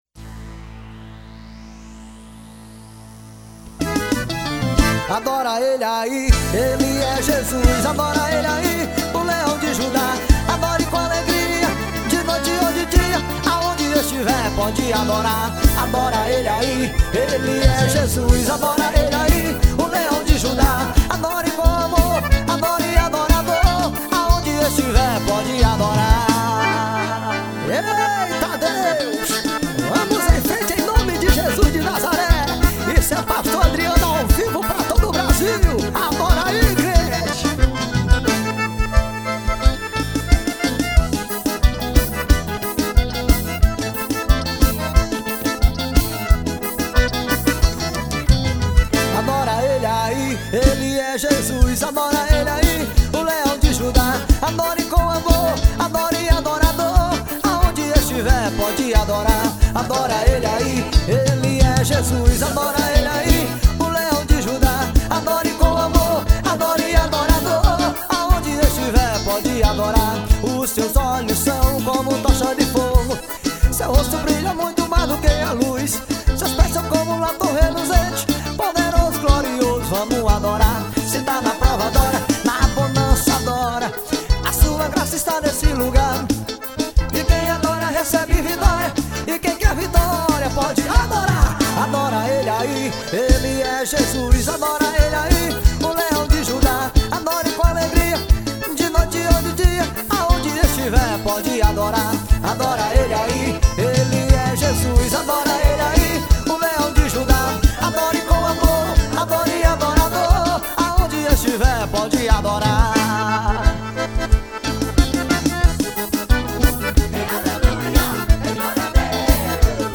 gospel.